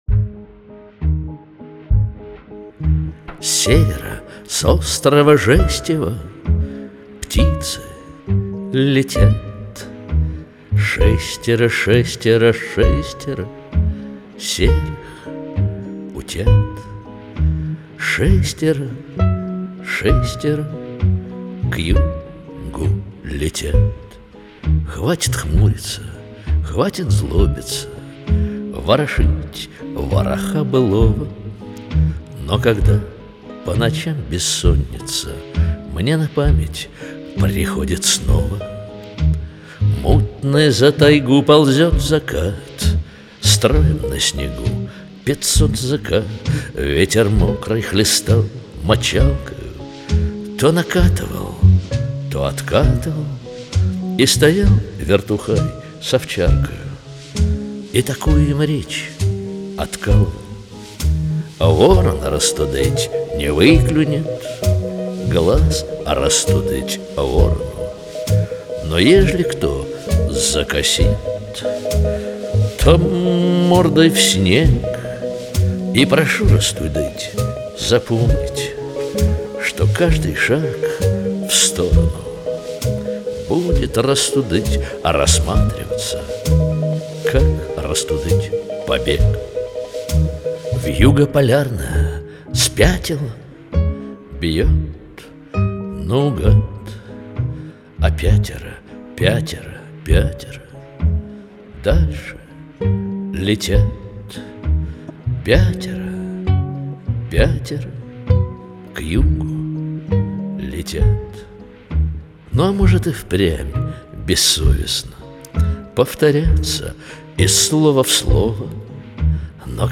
Жанр: авторская песня, rock